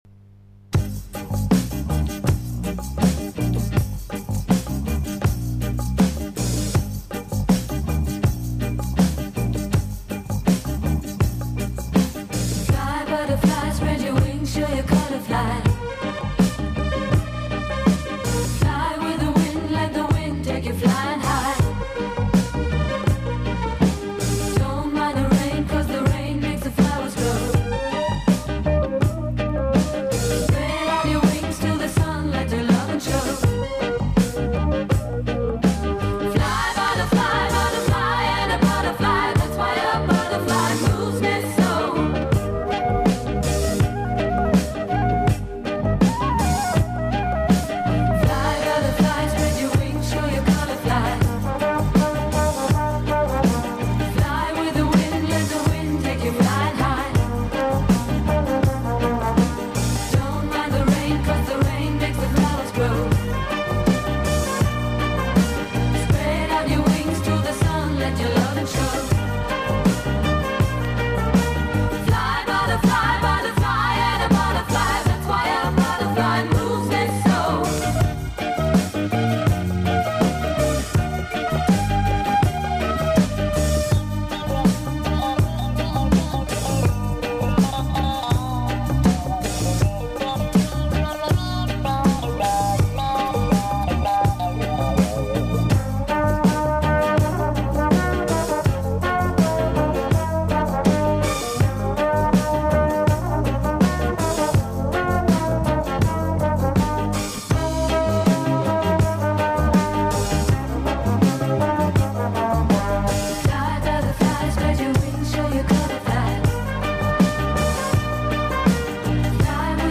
确都是异国情调比较浓厚的曲子。